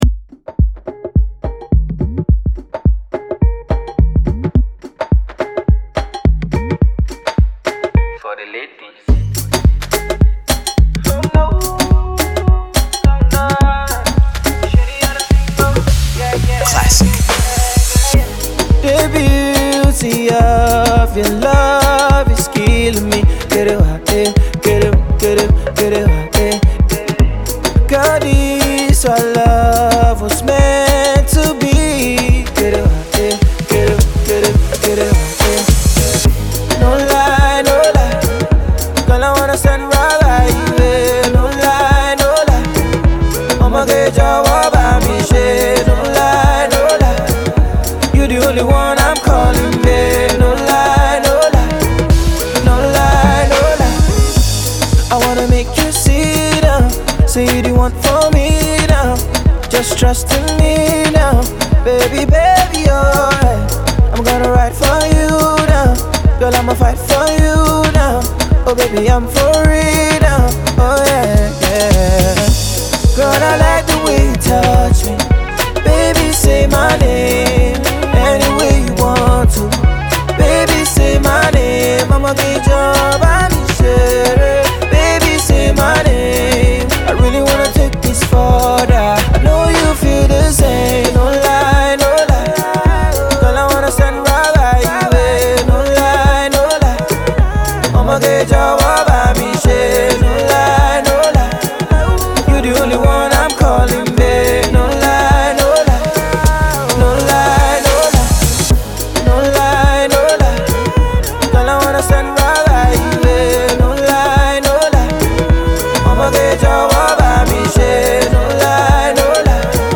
calm and soothing freestyle